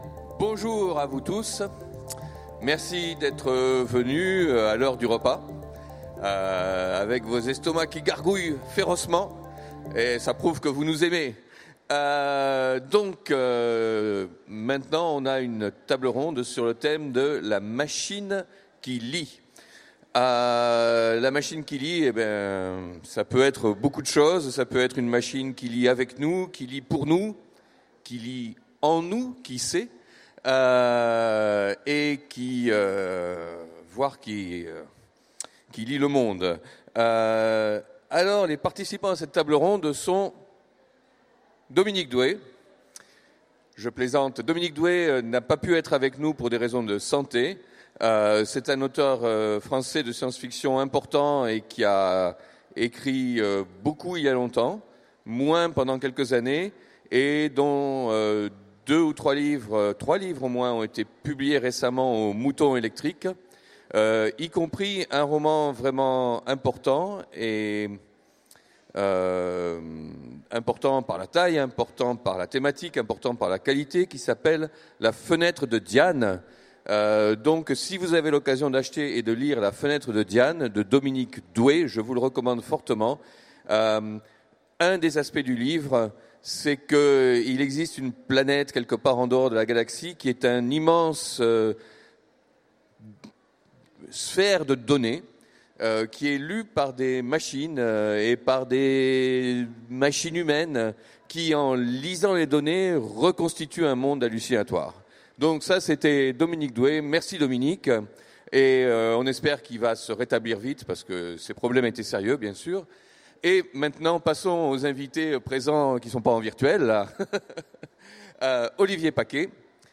Utopiales 2016 : Conférence La machine qui lit